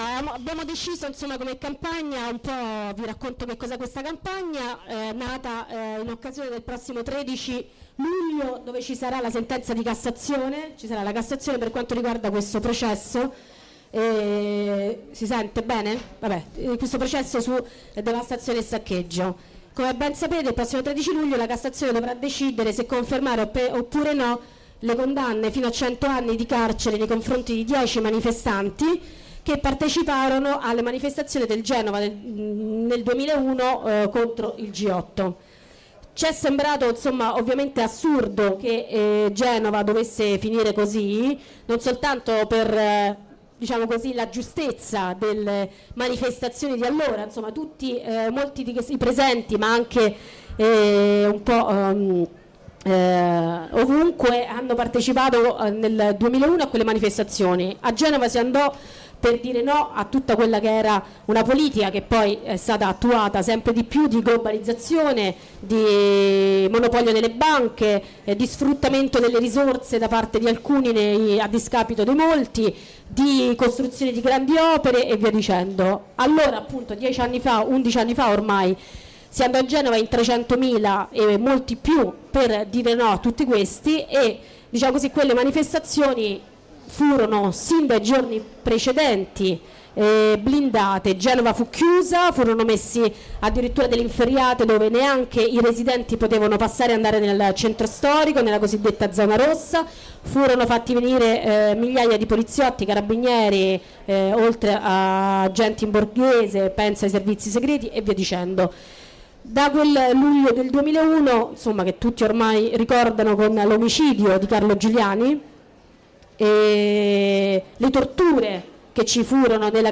Dibattito a Piazza dell'Immacolta, S.Lorenzo, 3 Luglio 2012, h. 19,30
dibattito_costruire_il_nemico.mp3